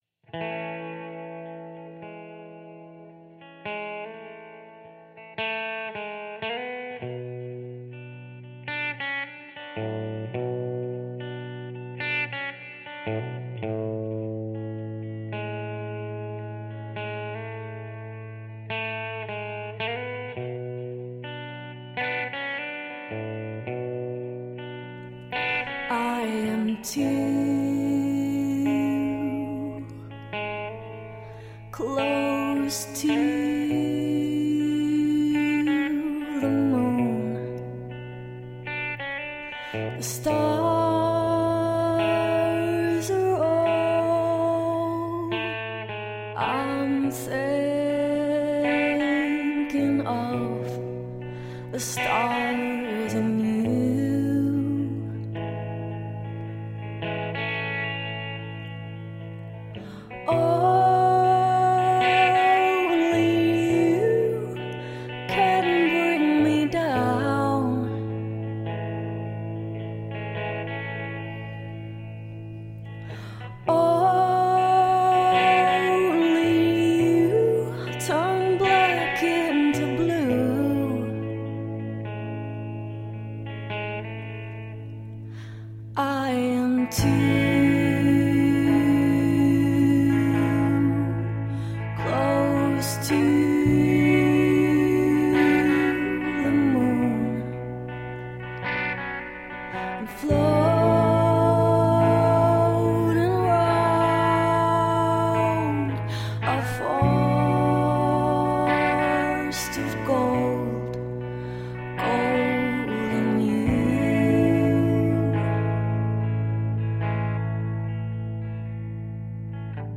Indie sound, southern soul.
Tagged as: Alt Rock, Folk-Rock